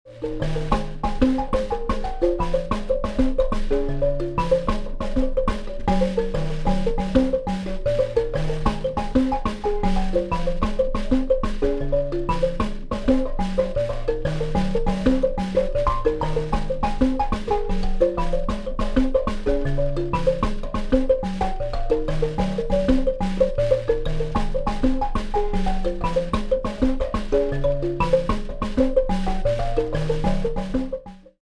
Here he sounds like 3 people playing!
Enjoy his beautiful singing, too.